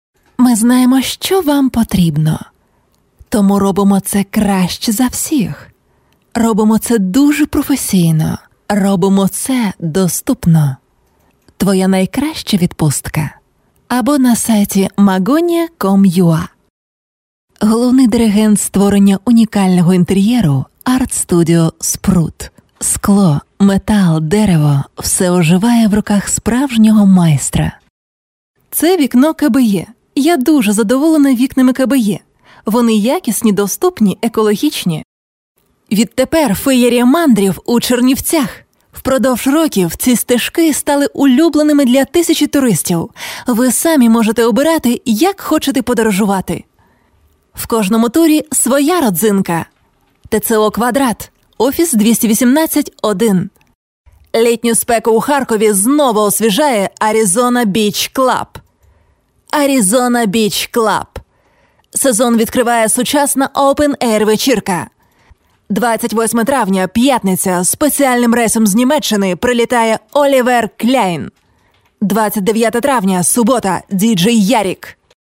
Ukraynaca Seslendirme
KADIN SESLER